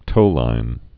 (tōlīn)